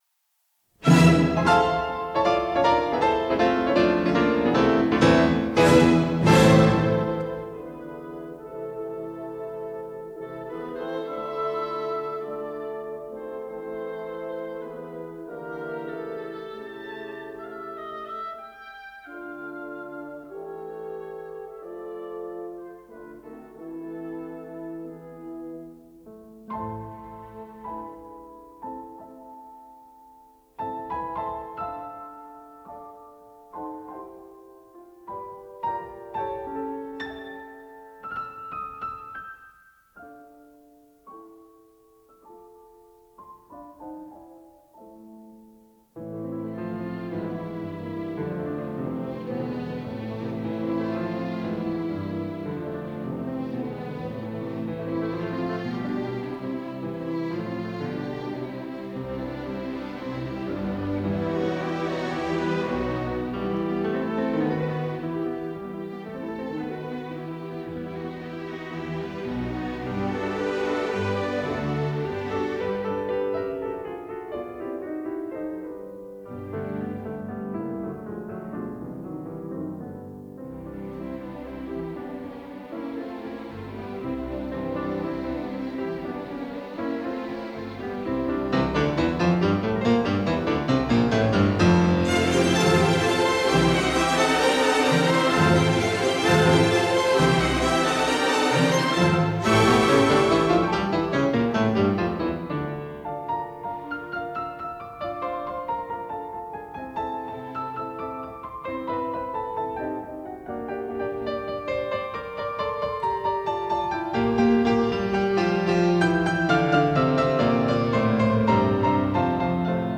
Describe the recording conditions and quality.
at Hadar Cinema, Tel Aviv